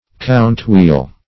Search Result for " count-wheel" : The Collaborative International Dictionary of English v.0.48: Count-wheel \Count"-wheel`\ (kount"hw?l`), n. The wheel in a clock which regulates the number of strokes.